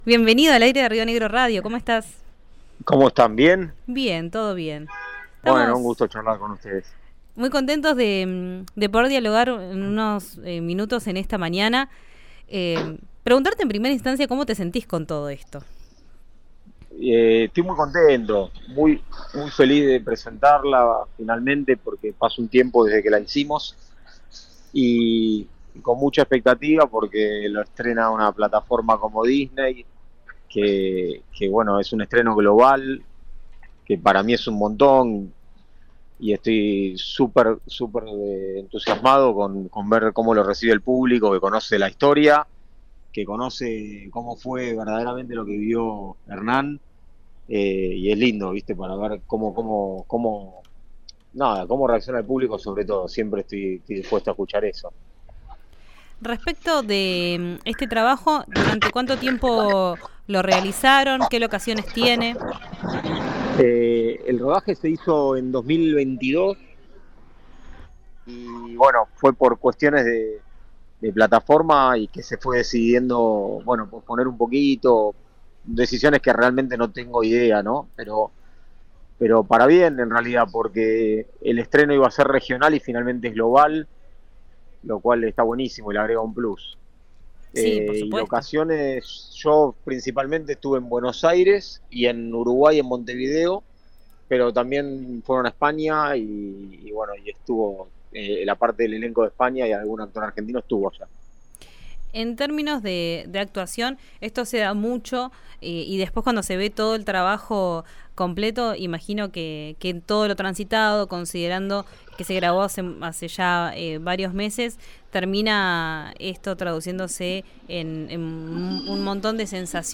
Escuchá la entrevista con RÍO NEGRO RADIO y Mirá el Trailer.
El actor argentino habló sobre la producción en RÍO NEGRO RADIO.